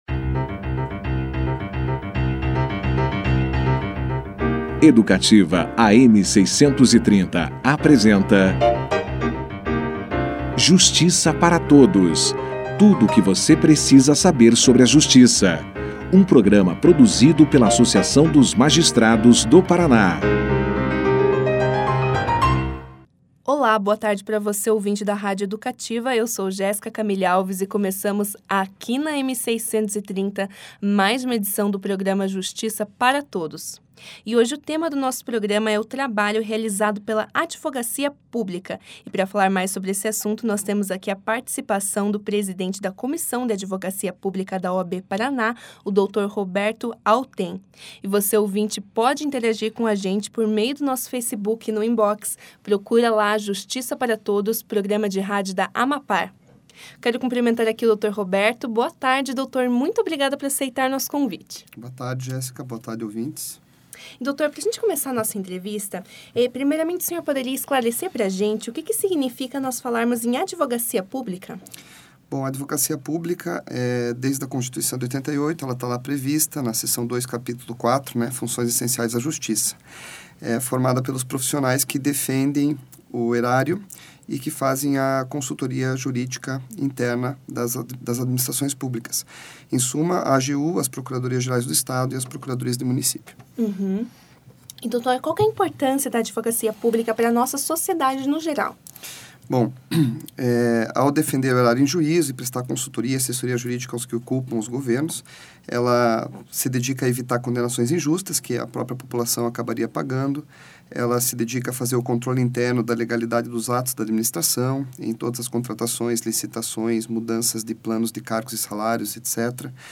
No início da entrevista